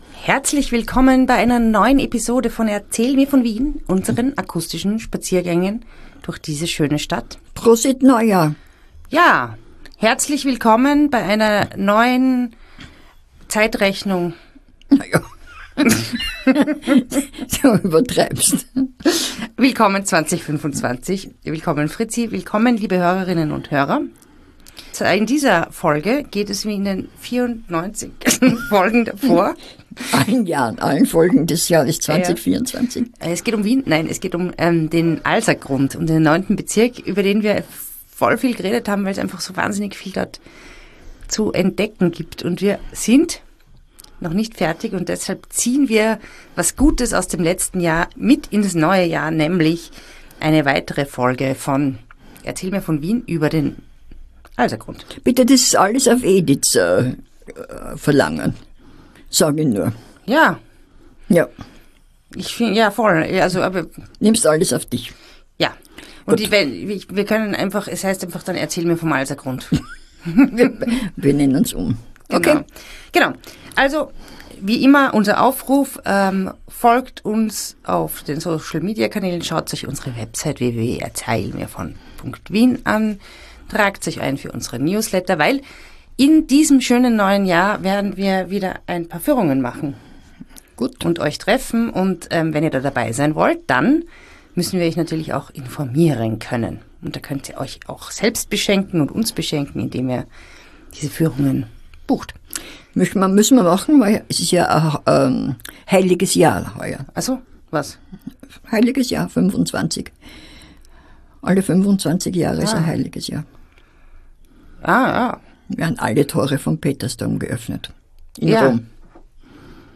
Die beiden spazieren durch Wien und unterhalten sich über bekannte und unbekannte Orte, prägende Persönlichkeiten und die vielen kuriosen Geschichten, die es an allen Ecken, in allen Grätzeln und Bezirken Wien zu entdecken gibt.